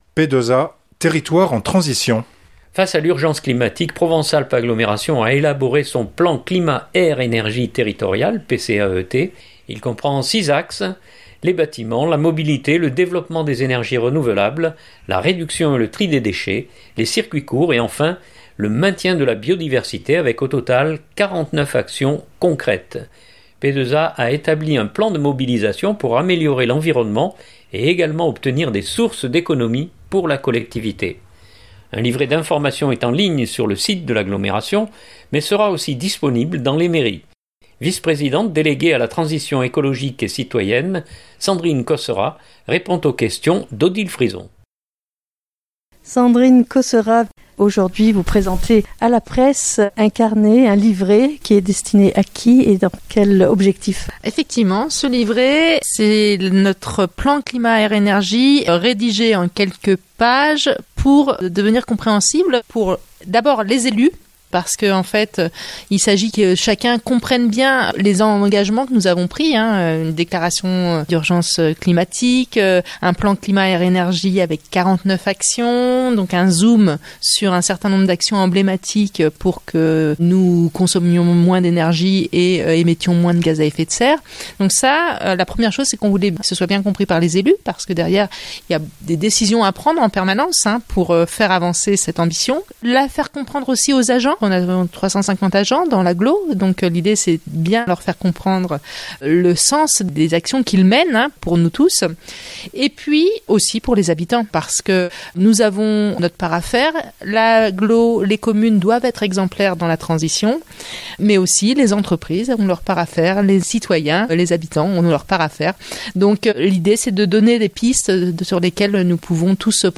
Vice-présidente déléguée à la transition écologique et citoyenne